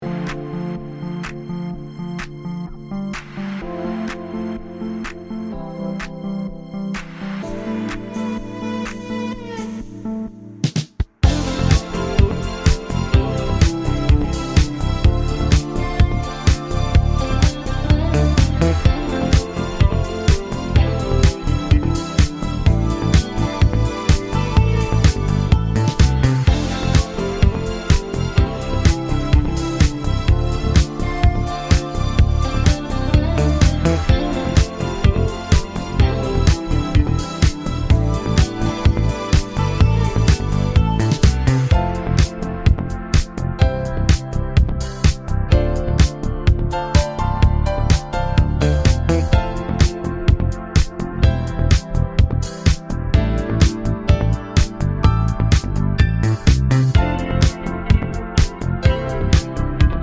no Backing Vocals Pop
Pop (2020s)